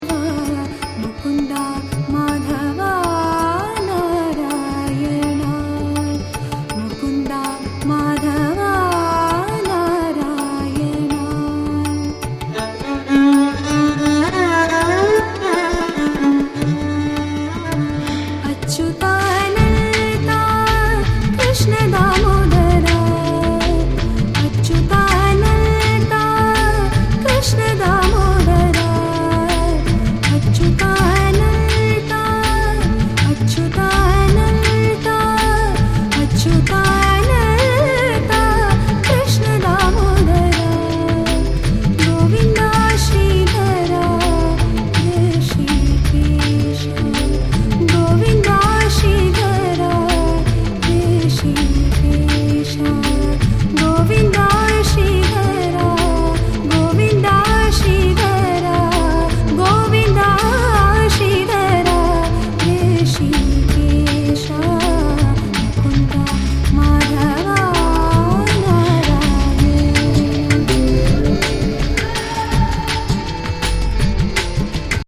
エイジアン・ダンス・ミュージック！！！
WORLD / MIX CD / CD